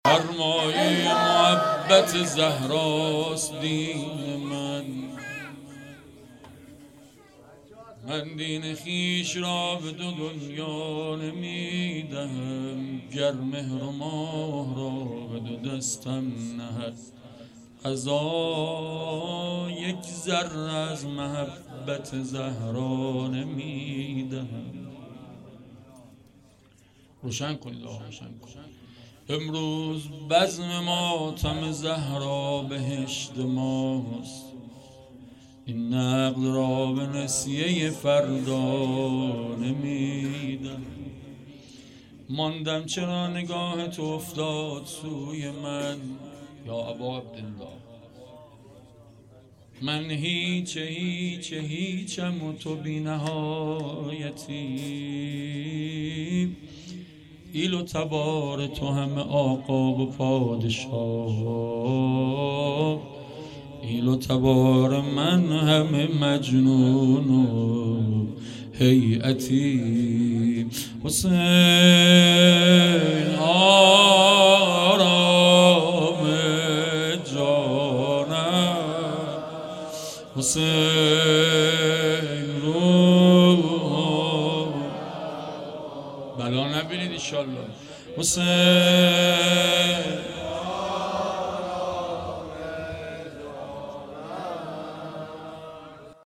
سرمایه محبت زهراست دین من _ شعرپایانی
شهادت امام جواد علیه السلام